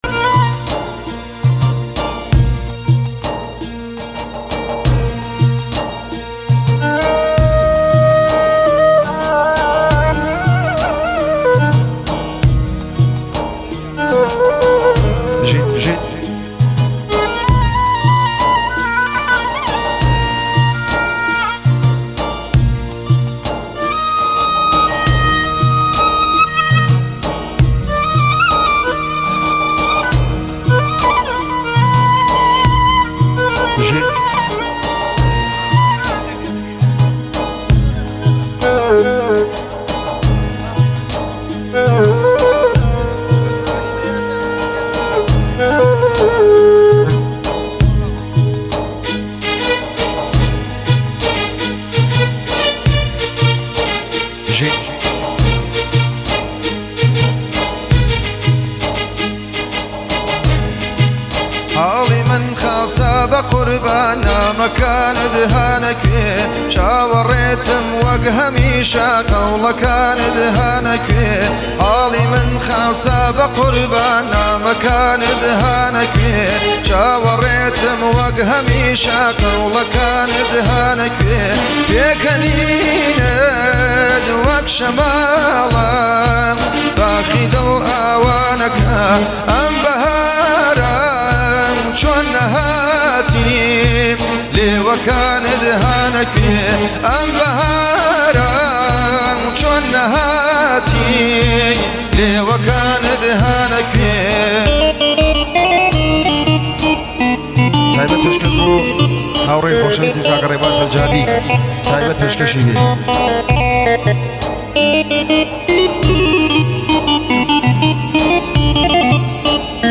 کردی
مجلسی